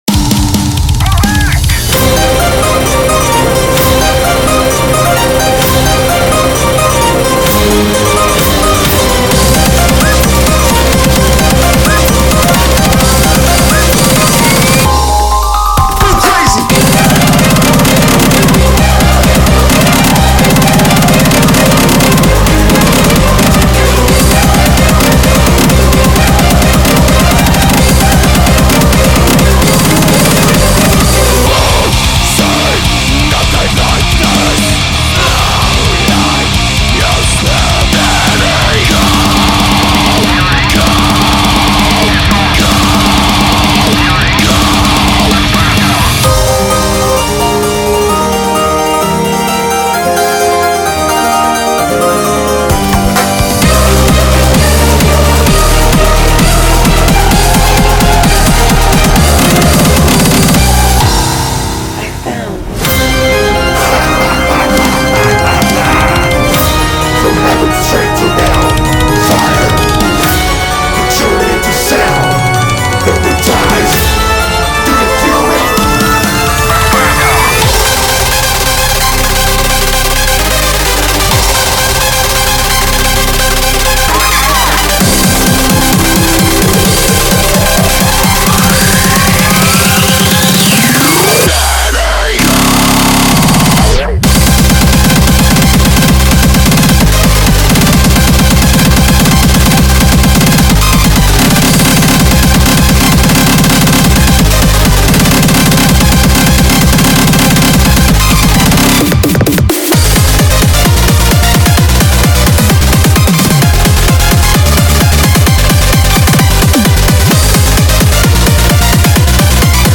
BPM65-260
Audio QualityPerfect (High Quality)
CommentsHahaha, kicks go brrrrrr.